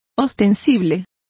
Complete with pronunciation of the translation of ostensible.